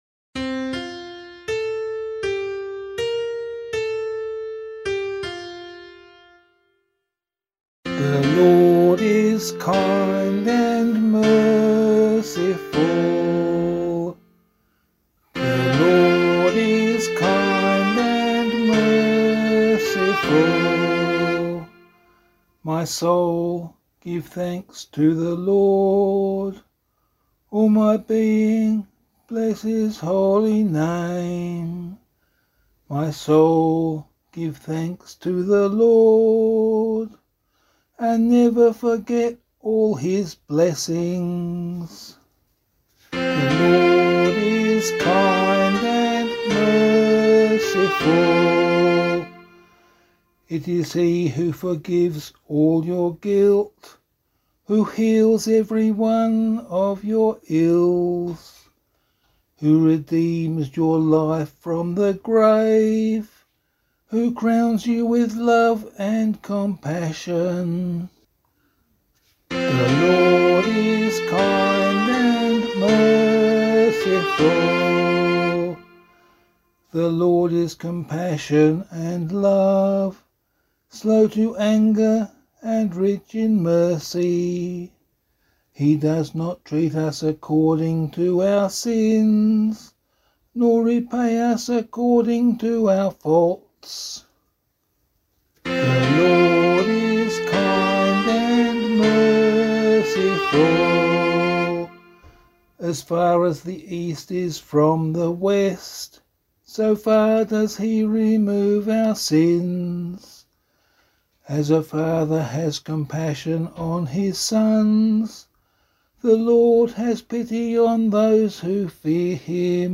041 Ordinary Time 7 Psalm C [LiturgyShare 6 - Oz] - vocal.mp3